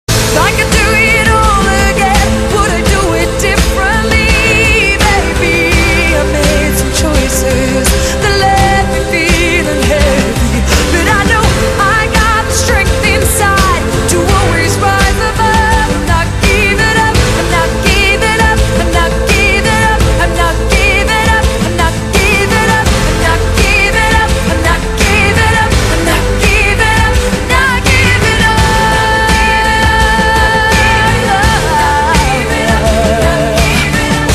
M4R铃声, MP3铃声, 欧美歌曲 68 首发日期：2018-05-14 14:14 星期一